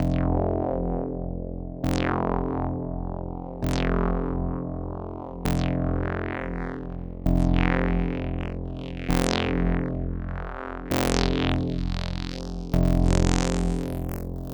synth.wav